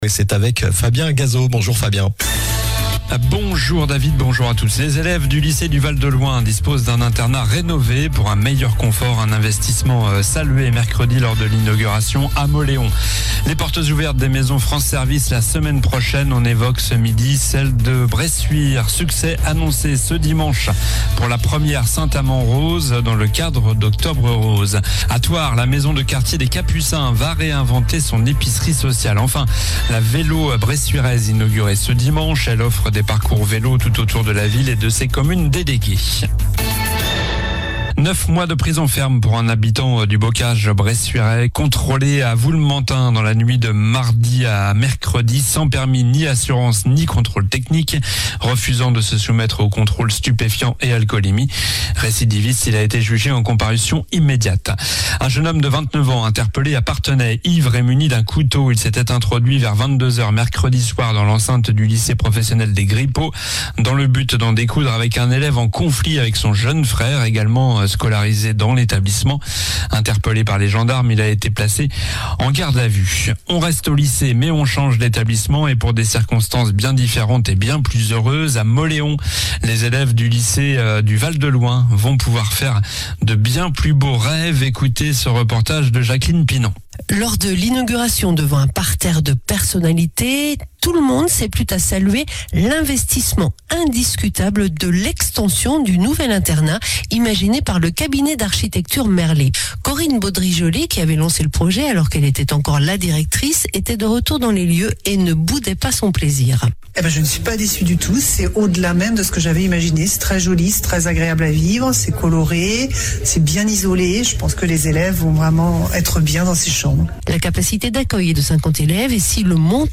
Journal du vendredi 4 octobre (midi)